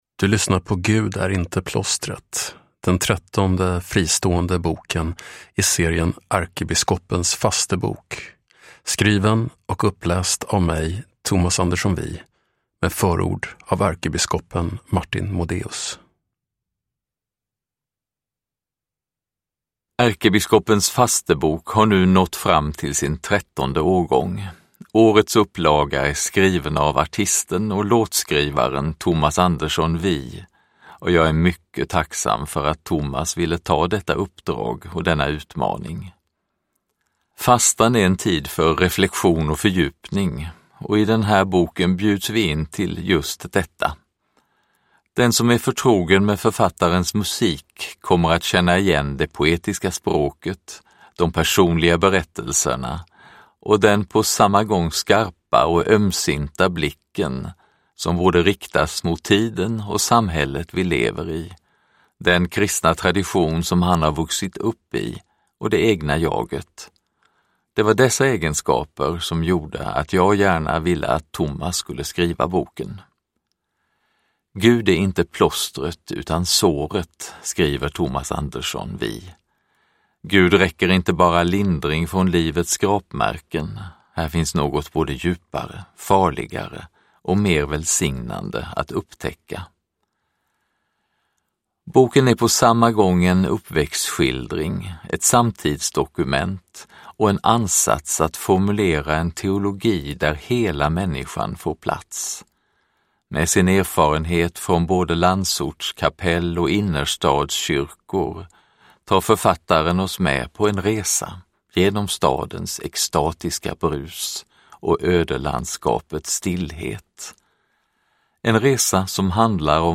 Uppläsare: Tomas Andersson Wij
Ljudbok